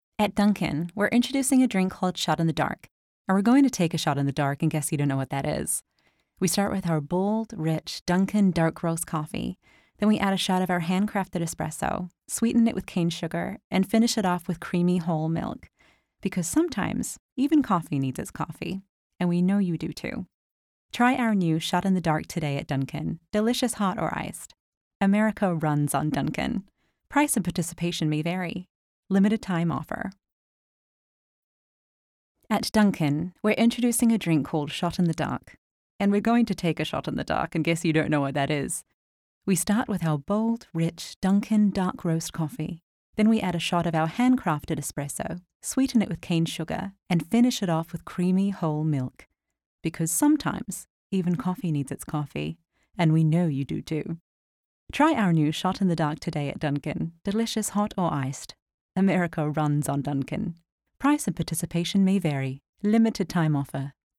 Adult, Young Adult
standard us
commercial